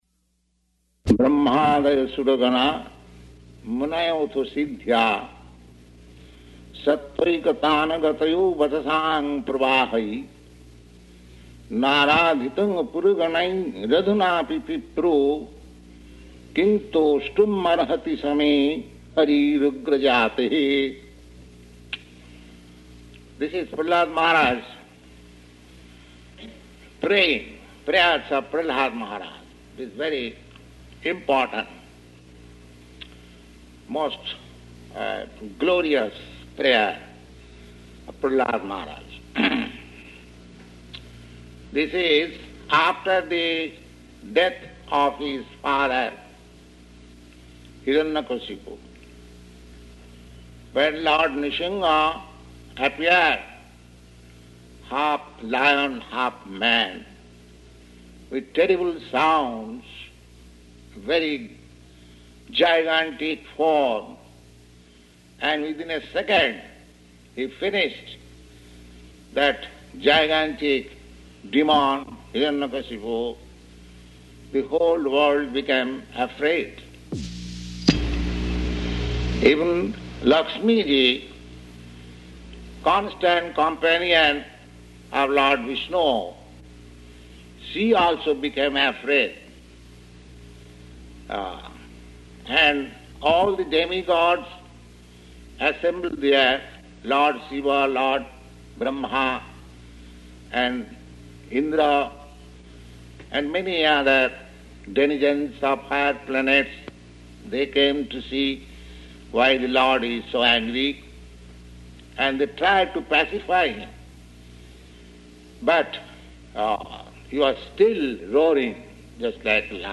Location: Montreal
[Poor audio]